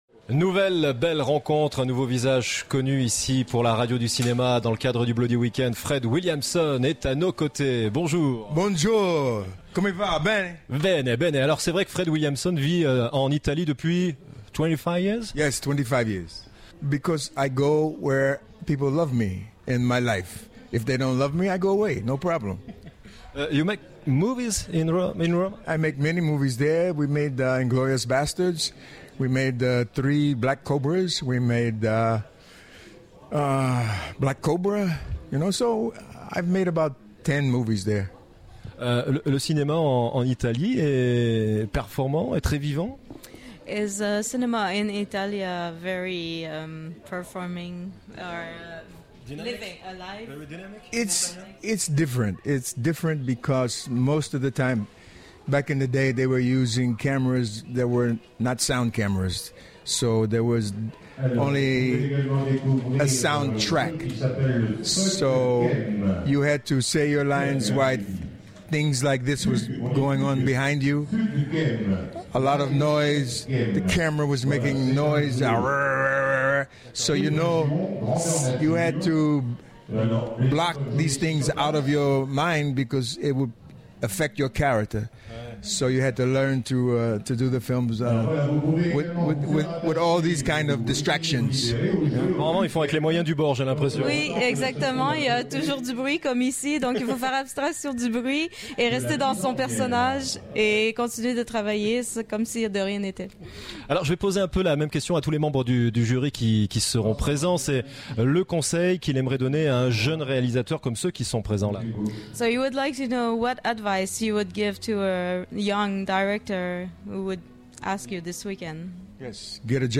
Interview de Monsieur FRED WILLIAMSON !